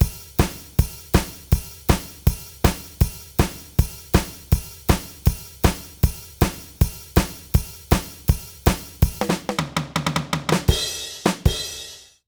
British ROCK Loop 158BPM.wav